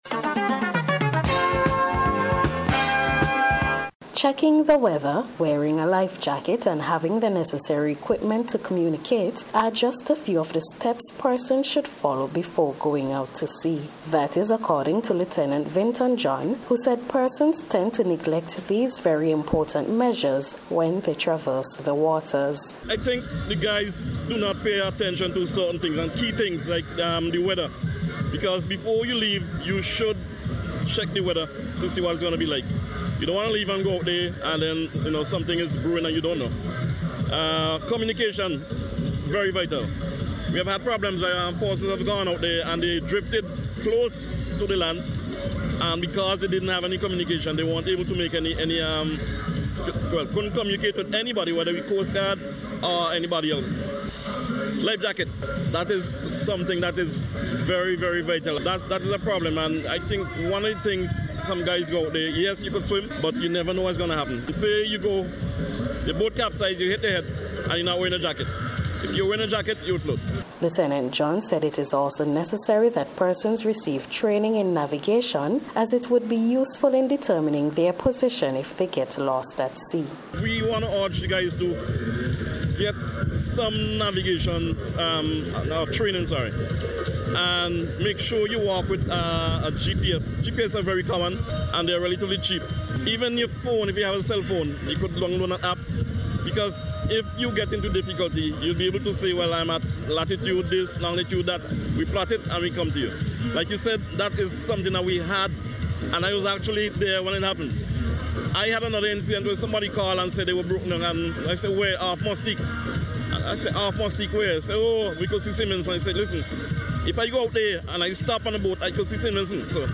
NBC’s Special Report for June 7th 2022